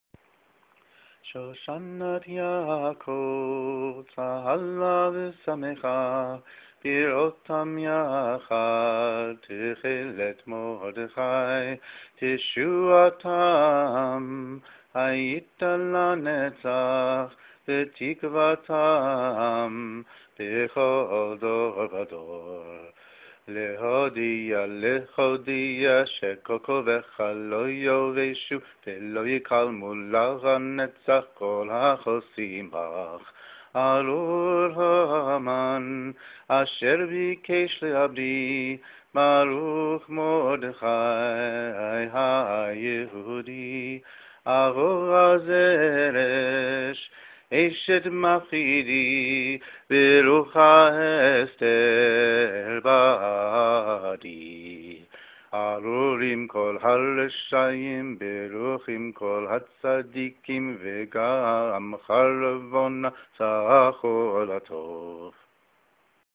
(recorded on an iPhone because my iRiver stopped working – sorry for the lower quality)